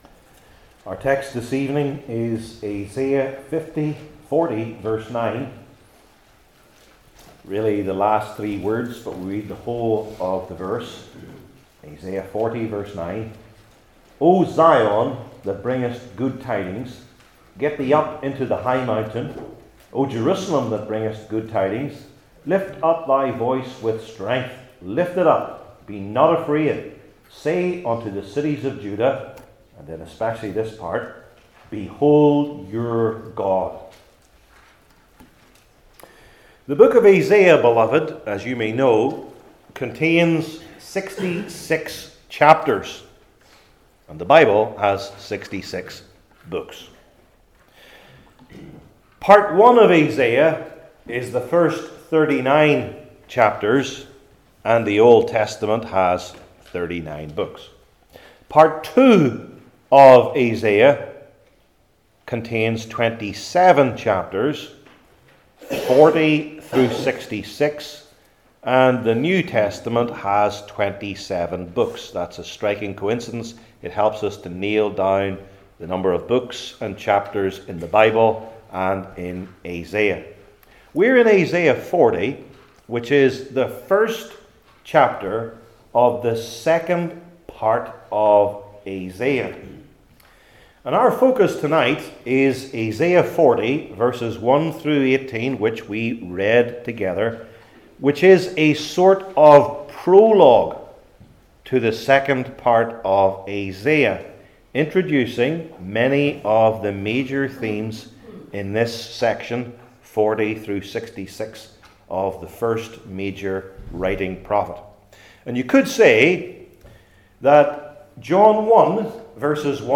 Old Testament Individual Sermons I. Perfections II.